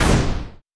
SFX item_card_fire_hit.wav